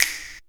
SINGLE HITS 0010.wav